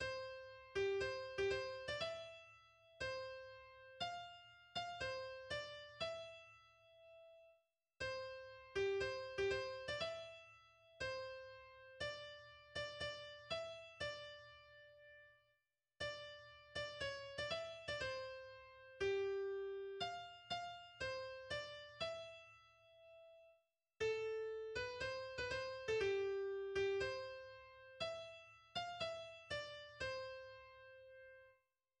作曲者 アメリカ民謡